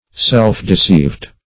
Search Result for " self-deceived" : The Collaborative International Dictionary of English v.0.48: Self-deceived \Self`-de*ceived"\, a. Deceived or misled respecting one's self by one's own mistake or error.
self-deceived.mp3